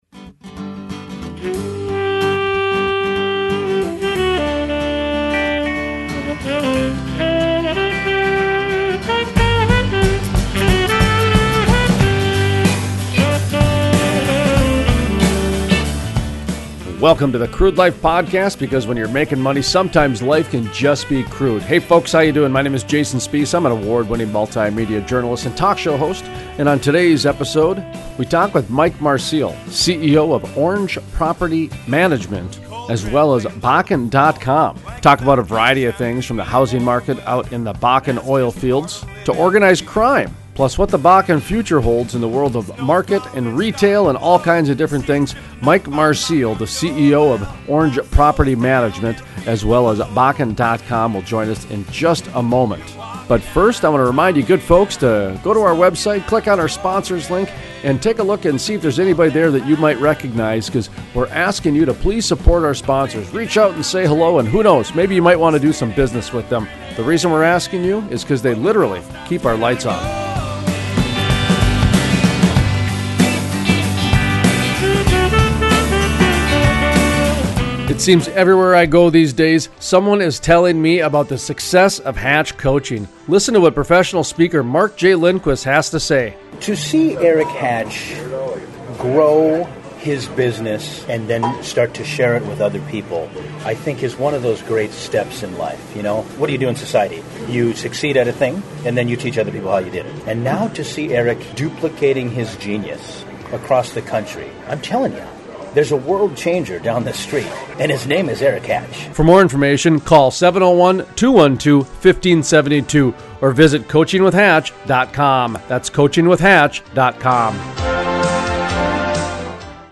The Crude Life Interview: Bakken housing costs down, quality of life up and organized crime